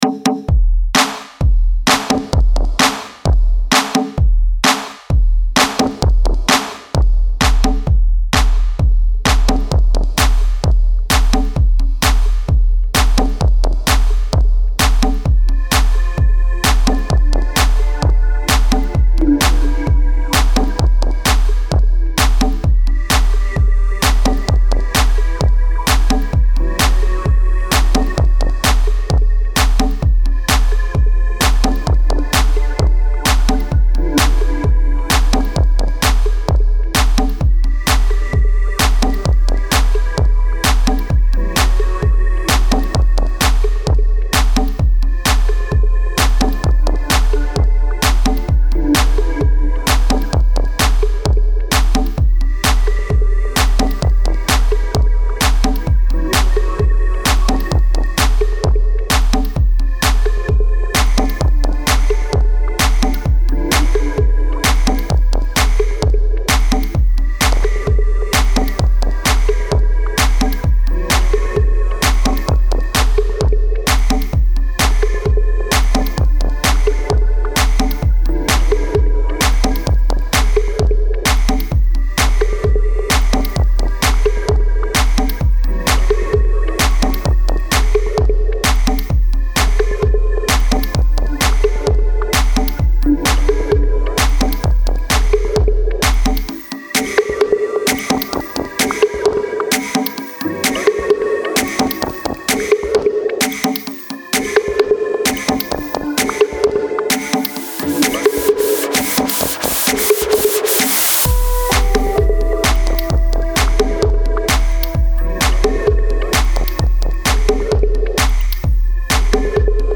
Musikstil: Tech-House